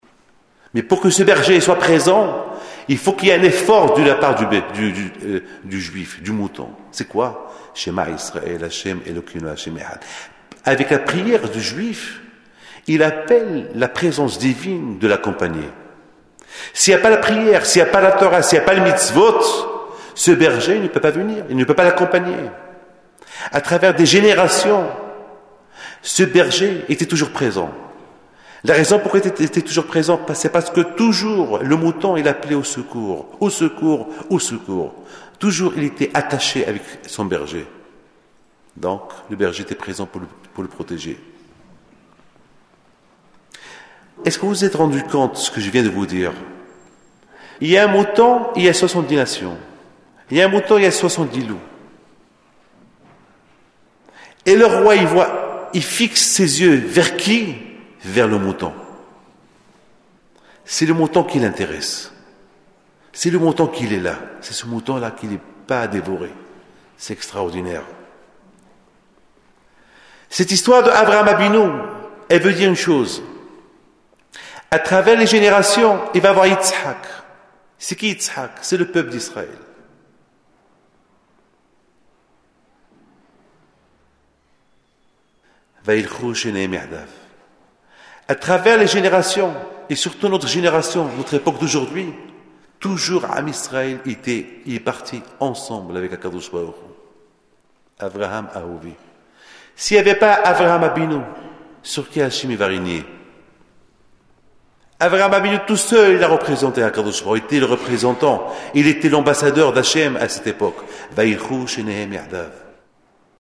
lors de la Veillée de Hoshana Rabba 5761 à l’Ecole Ozar Hatorah à Sarcelles dans la nuit de jeudi à vendredi 20 octobre 2000 devant une assistance de fidèles très nombreuse et fervente.